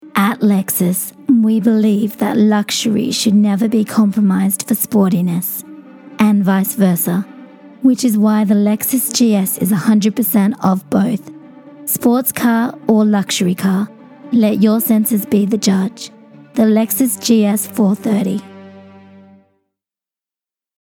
Female
English (Australian)
Yng Adult (18-29), Adult (30-50)
Engaging
Soothing
Gentle
Sexy
Radio Commercials